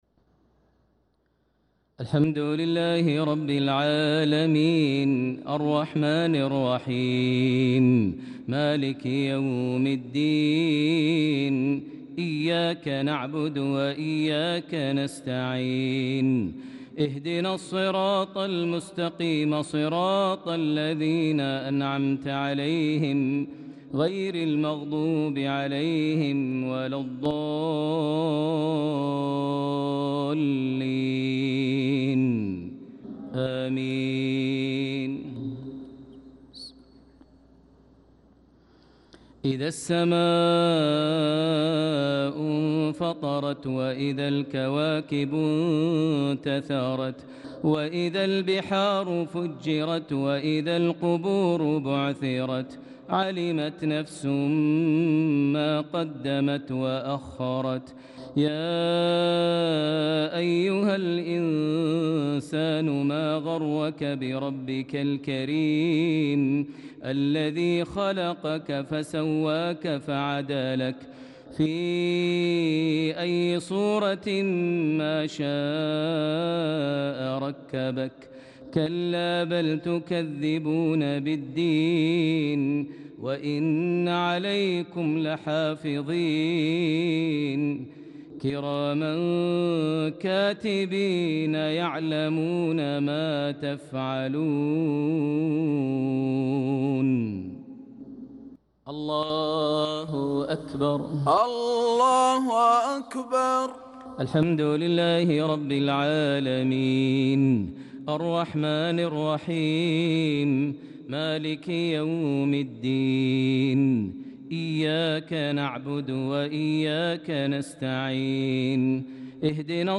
صلاة العشاء للقارئ ماهر المعيقلي 1 ذو الحجة 1445 هـ
تِلَاوَات الْحَرَمَيْن .